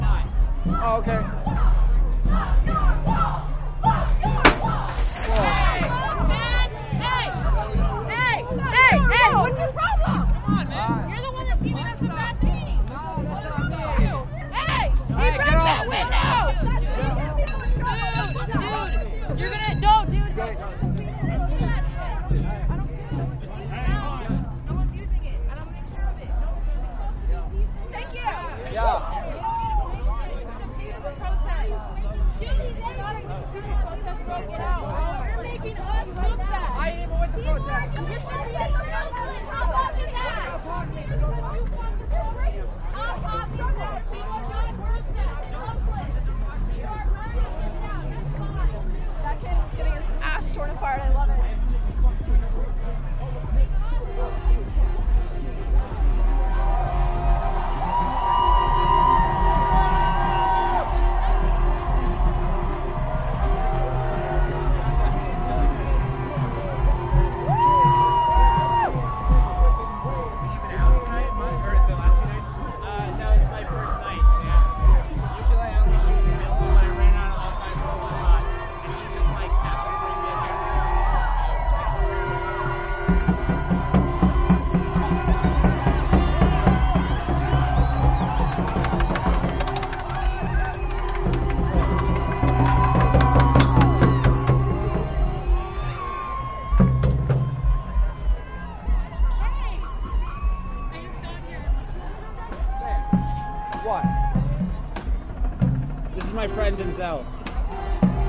Portland, USA: A protester breaks a window and is called out on it by other protesters.